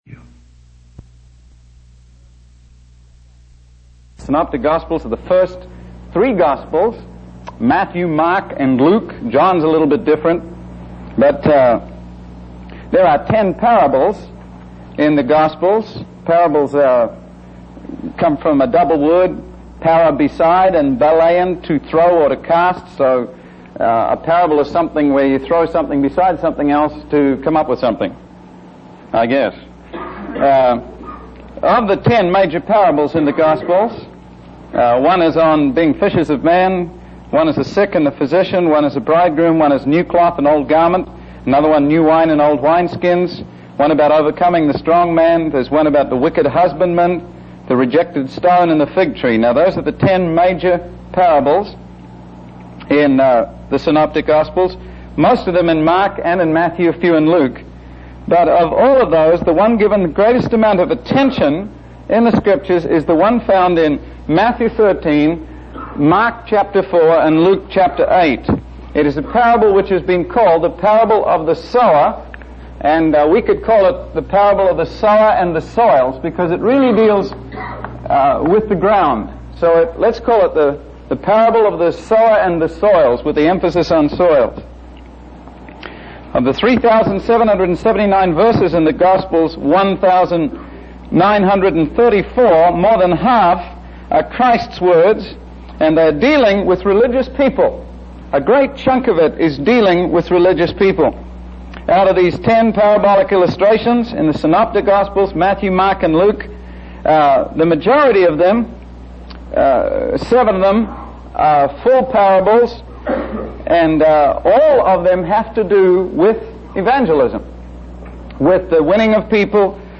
In this sermon, the preacher focuses on the parable of the sower found in the Bible. The main purpose of this parable is to emphasize the importance of genuine conversion and the preparation of the heart to receive the gospel.